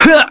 Fluss-Vox_Damage_kr_02.wav